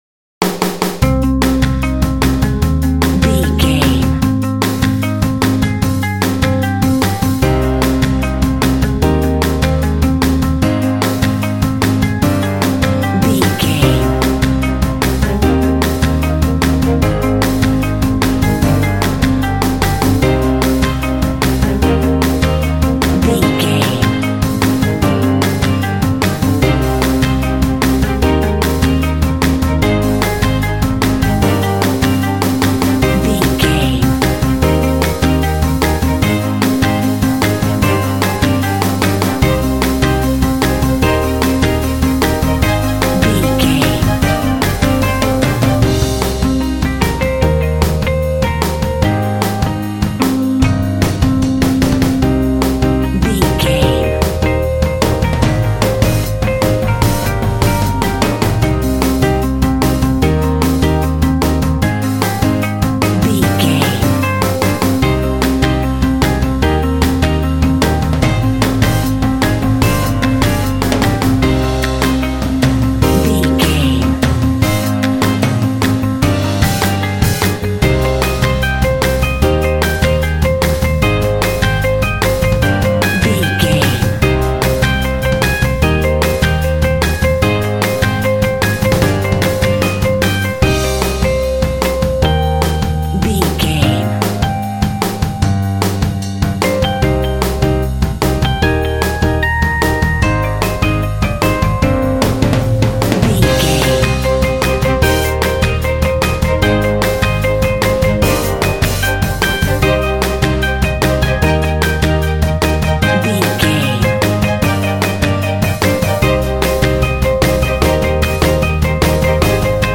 This light symphonic rock track will pump you up.
Epic / Action
Uplifting
Aeolian/Minor
Fast
driving
piano
electric guitar
drums
bass guitar
strings
symphonic rock
cinematic